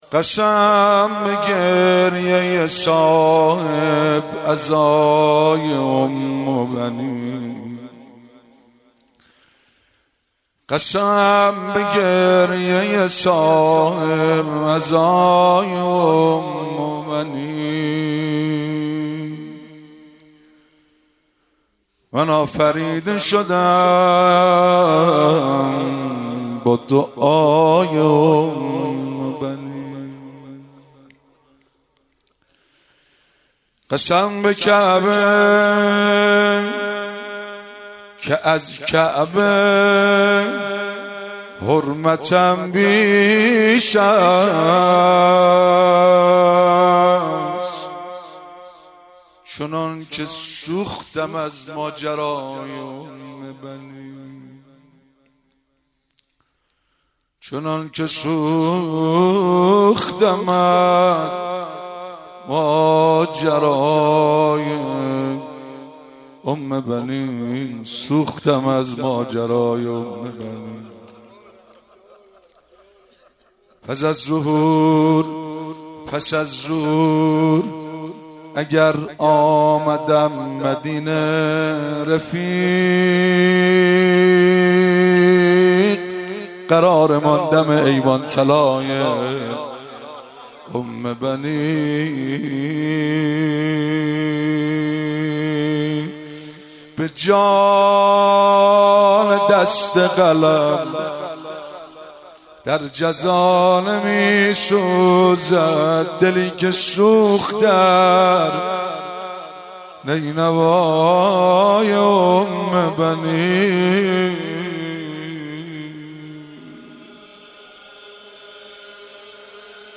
9 اسفند 96 - هیئت فاطمیون - روضه